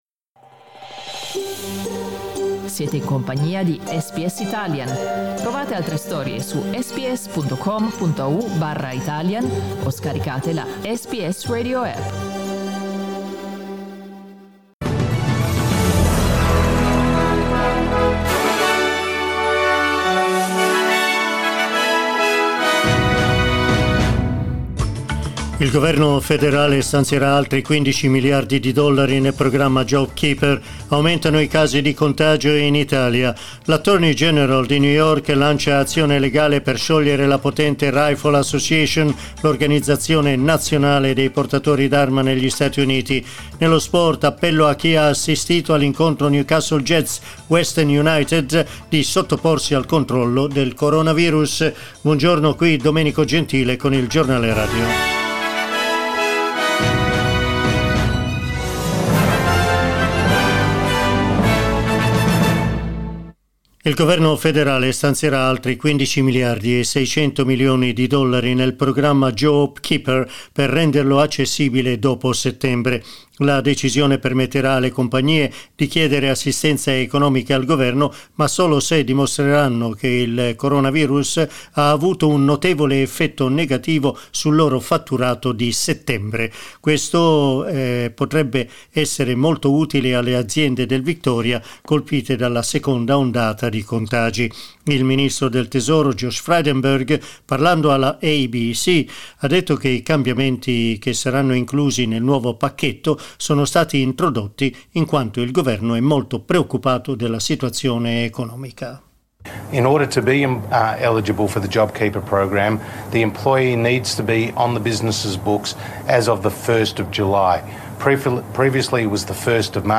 Our news bulletin in Italian